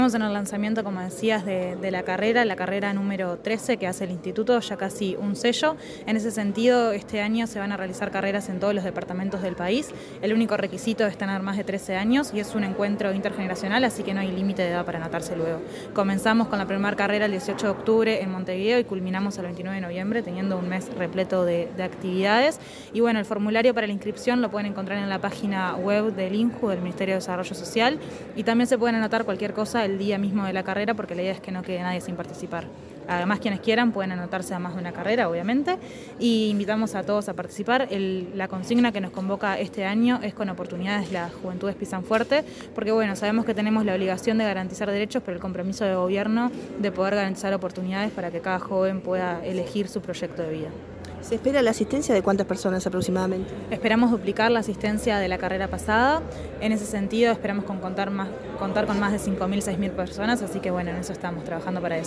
Declaraciones de la presidenta del INJU, Eugenia Godoy